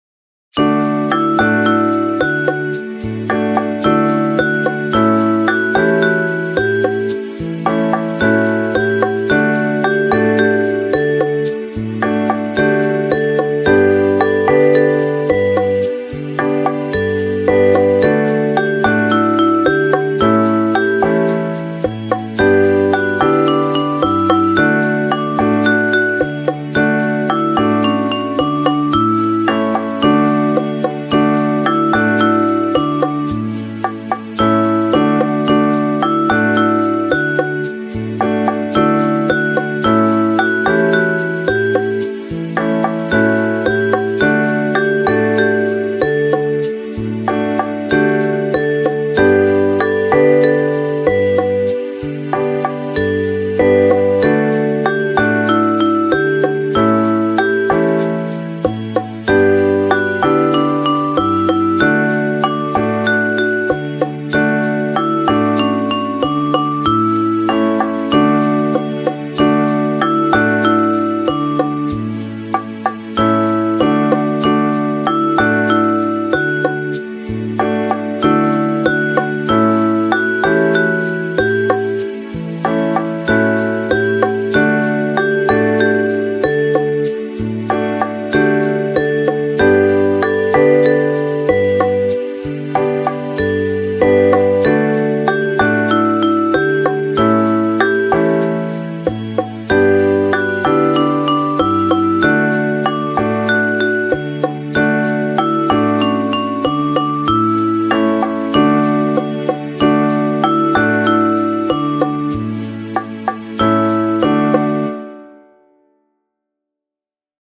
Full Ensemble